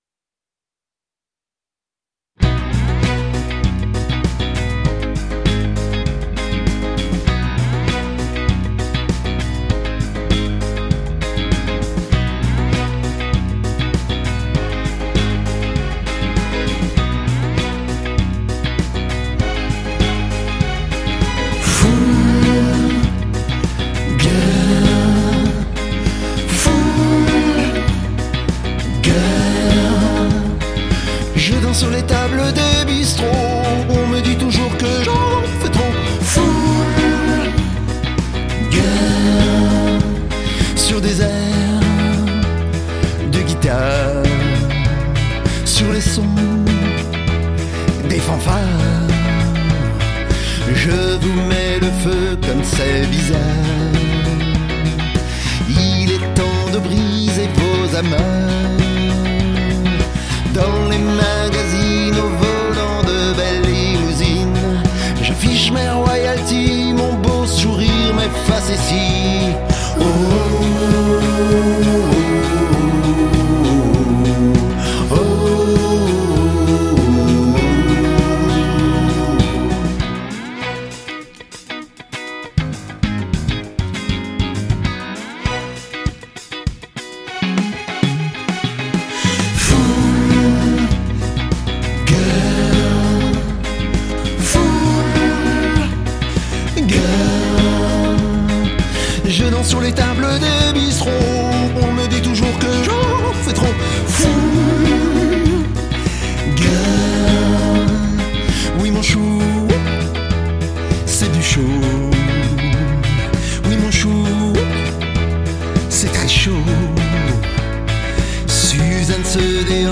De la pop avec une pointe disco très fun!
Enregistrement studio: basse & batterie
Enregistrement des cuivres